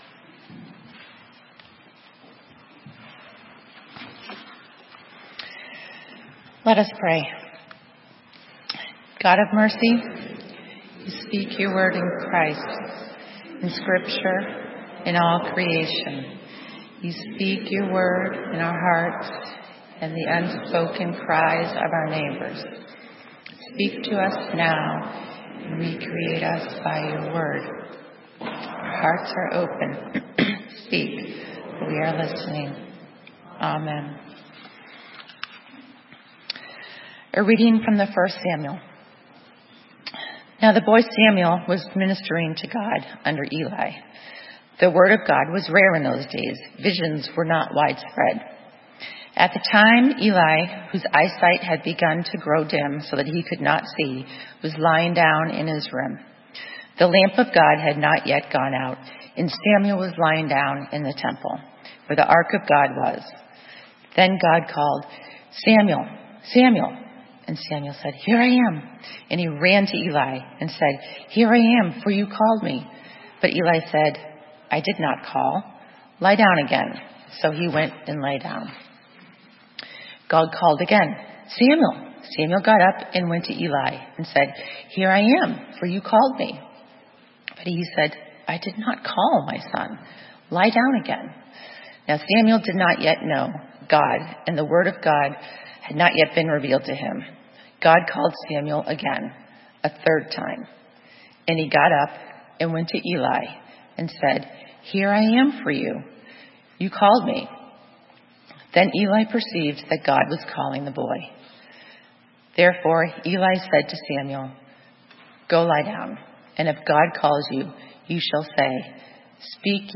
Sermon:Servant listening - St. Matthew's UMC